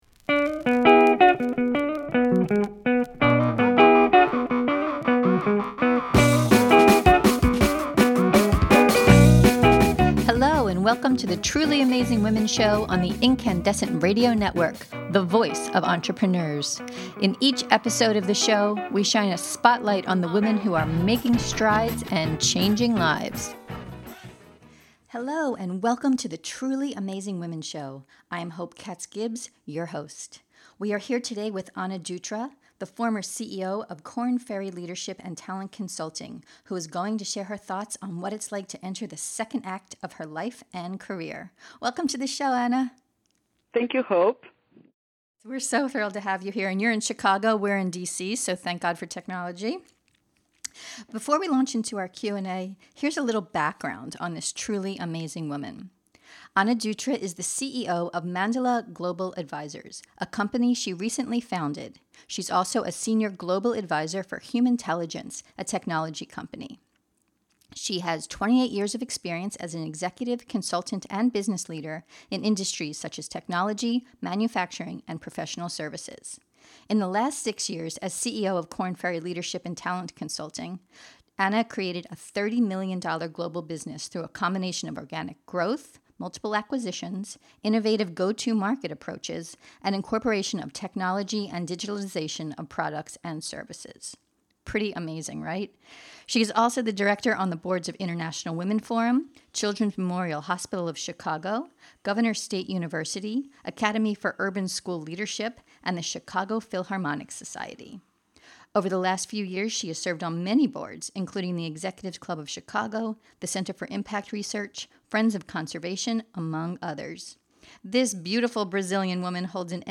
In this podcast interview we dive into the topic, including: What is the essence of the Dilemma?